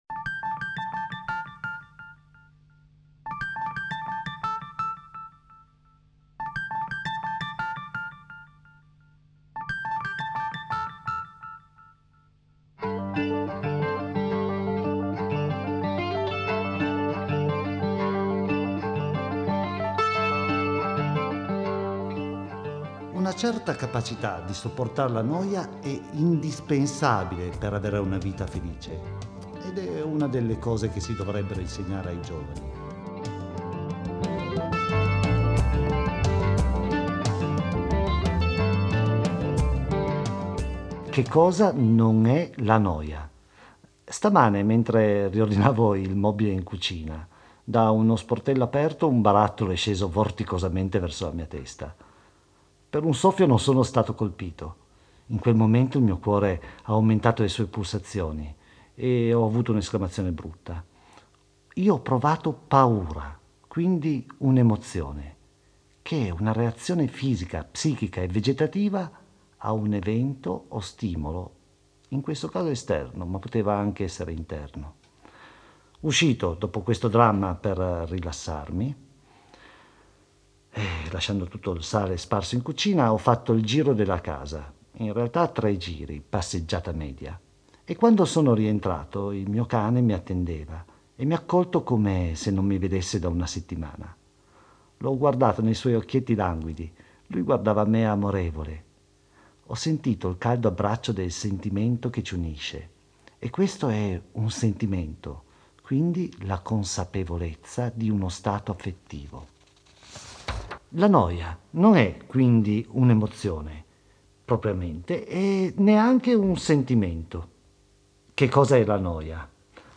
un piccolo viaggio con musica parole e aforismi in uno stato d'animo che oggi proviamo tutti!